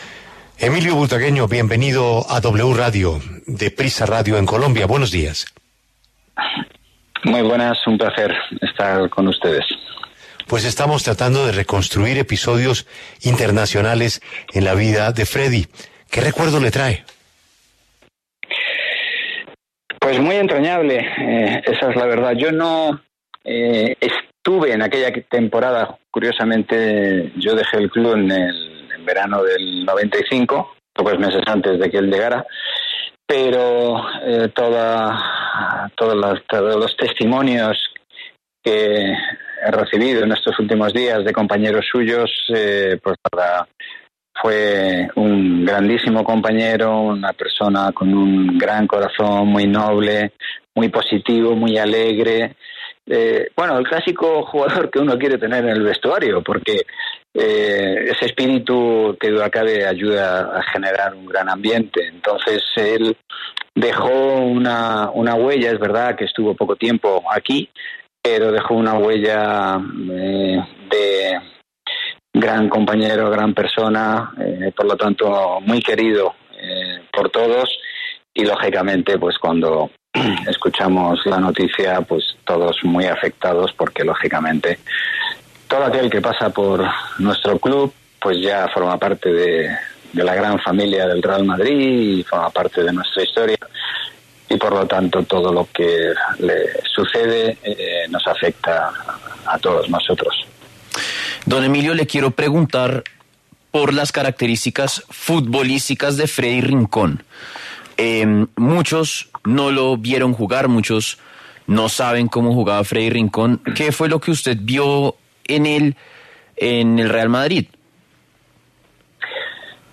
En el encabezado escuche la entrevista completa con Emilio Butragueño exfutbolista internacional español, quien fue dos veces nombrado Balón de Bronce en 1986 y 1987.